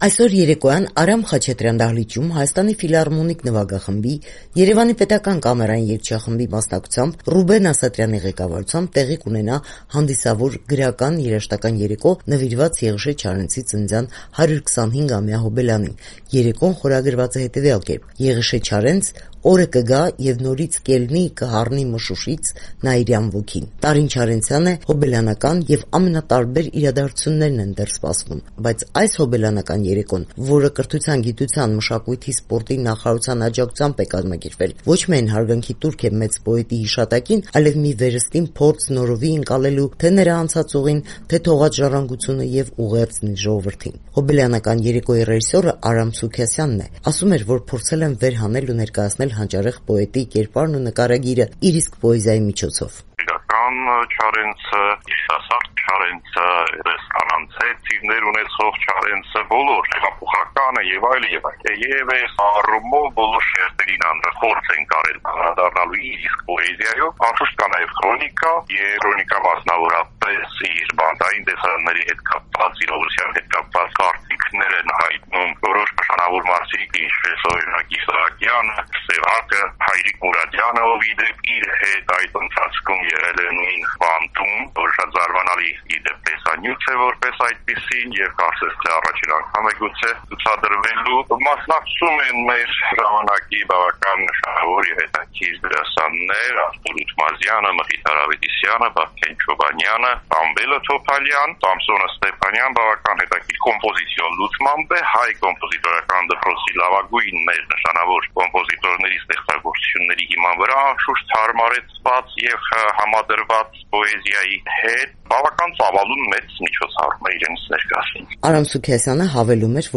«Եղիշե Չարենց․ Օրը կգա և նորից կելնի, կհառնի մշուշից նաիրյան ոգին». հոբելյանական համերգ Արամ Խաչատրյան համերգասրահում